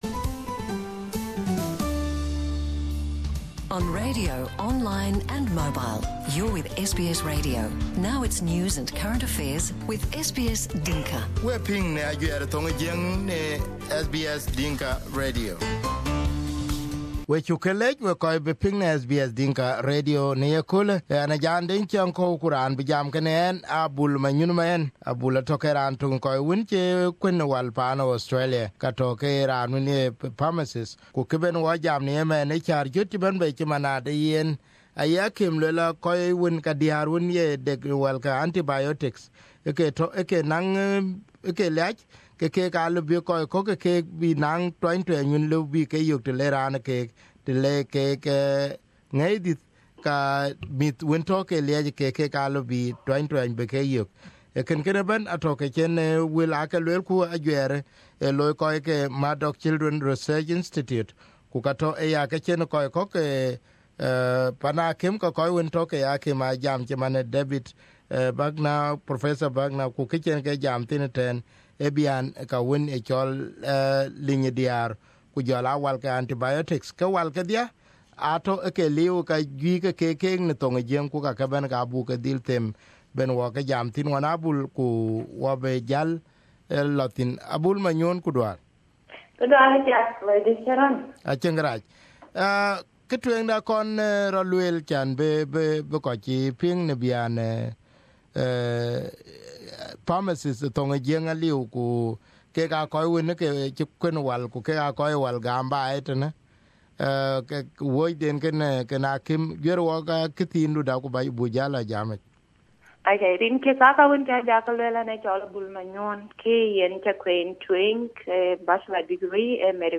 Here the interview on SBS Dinka Radio Share